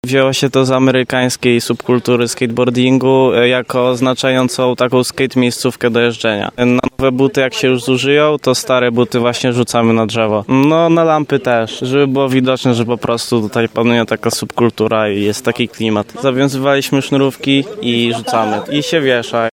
To zwyczaj skaterów. Mówi jeden z użytkowników toru